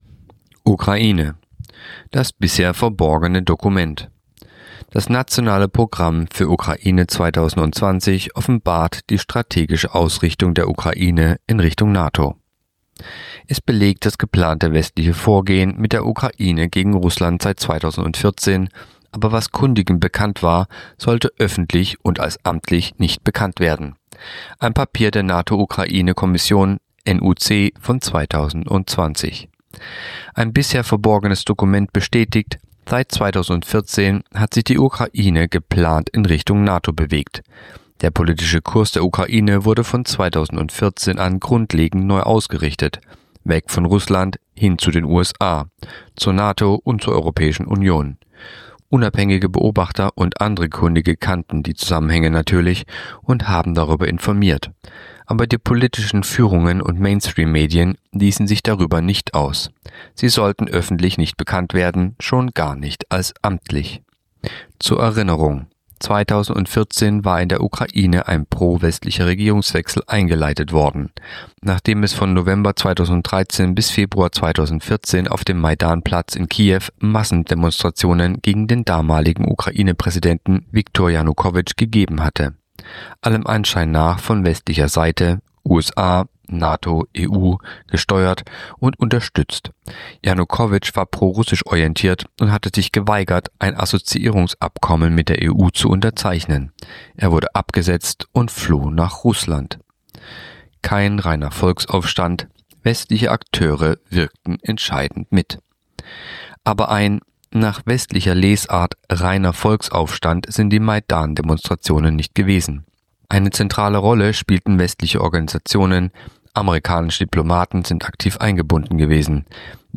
Kolumne der Woche (Radio)Das bisher verborgene Dokument